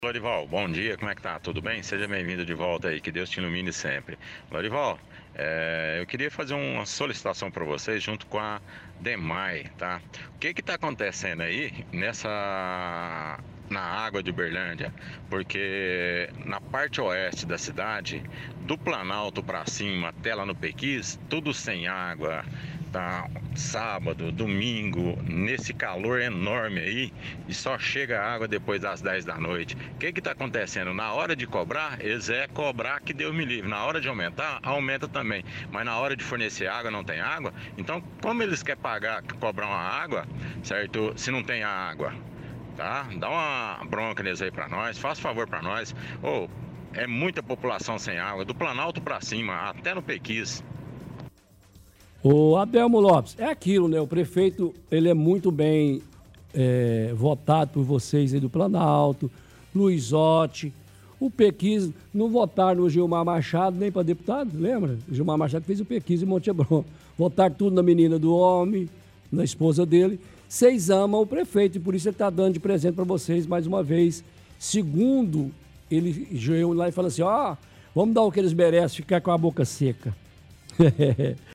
Caçoa com áudios antigos.
– Ouvinte fala sobre a falta de água no bairro Morada Nova.